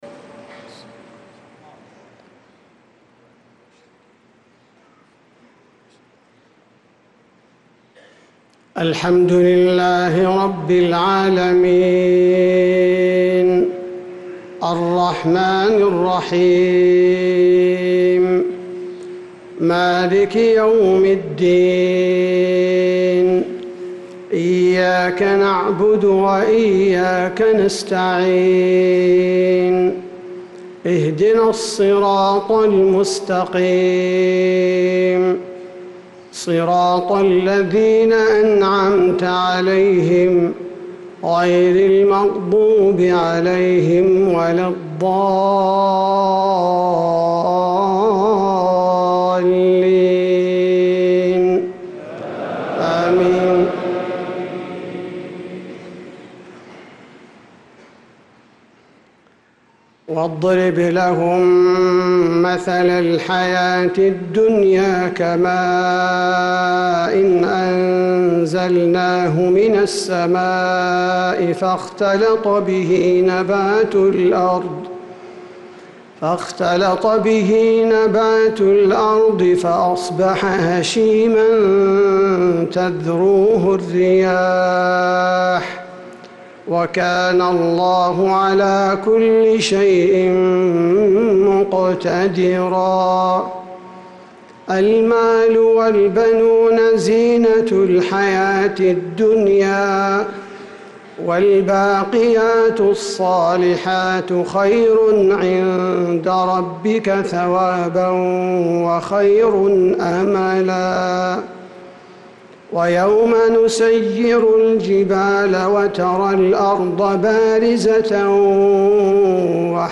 صلاة المغرب للقارئ عبدالباري الثبيتي 30 ربيع الأول 1446 هـ
تِلَاوَات الْحَرَمَيْن .